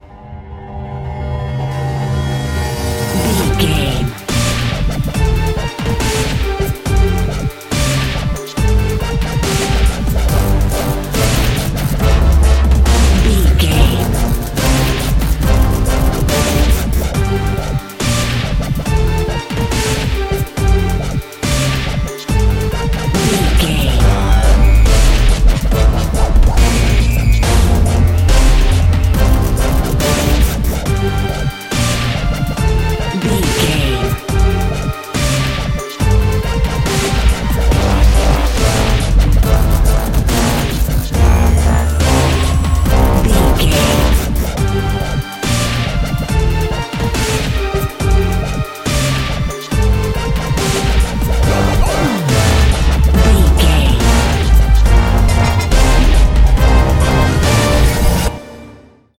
Aeolian/Minor
drum machine
synthesiser
drum beat
epic